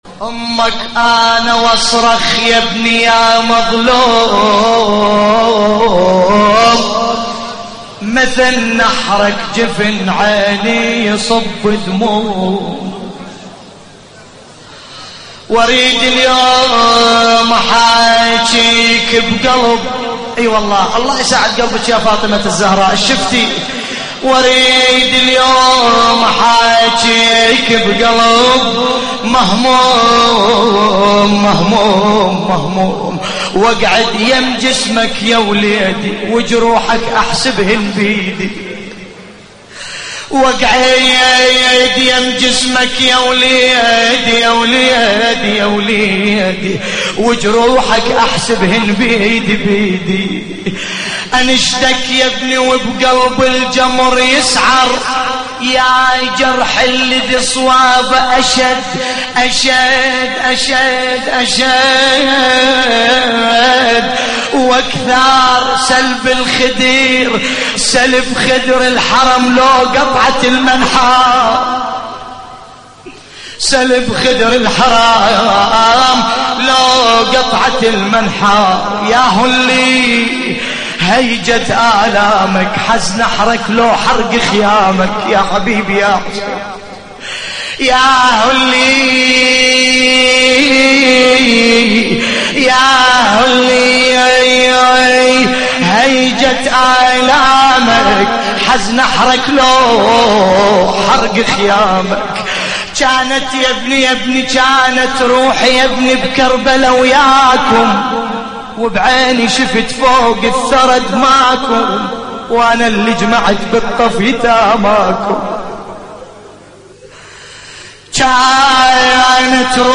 نعي لحفظ الملف في مجلد خاص اضغط بالزر الأيمن هنا ثم اختر